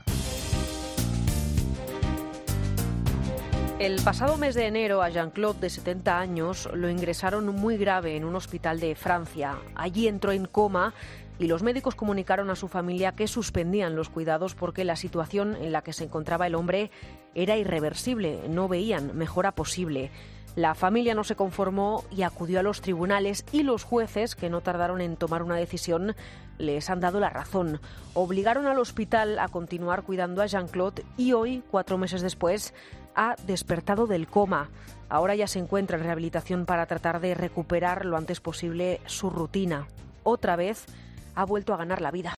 Presentado por Carlos Herrera, el comunicador más escuchado de la radio española, es un programa matinal que se emite en COPE, de lunes a viernes, de 6 a 13 horas, y que siguen cada día más de dos millones y medio de oyentes, según el EGM.